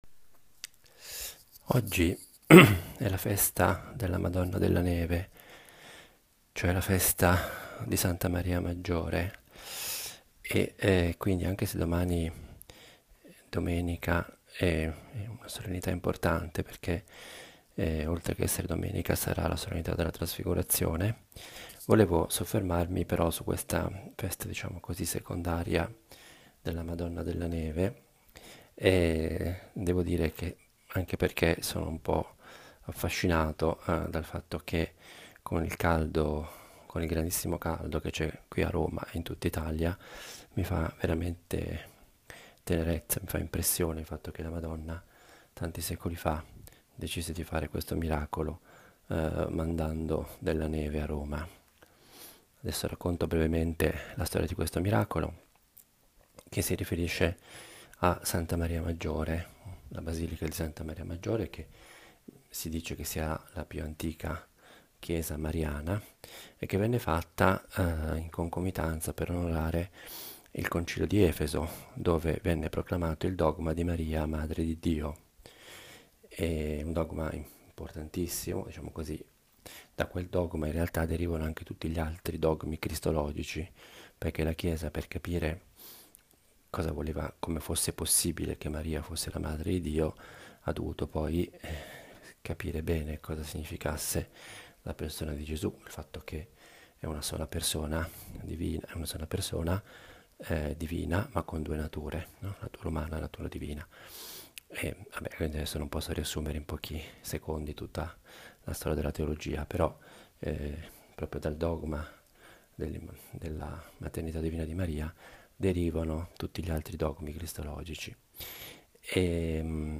Pausa caffè a Nazareth è una riflessione breve, di otto minuti, sul vangelo della domenica.
Vorrei avesse il carattere piano, proprio di una conversazione familiare. Io la intendo come il mio dialogo personale – fatto ad alta voce – con Dio e con la Madonna.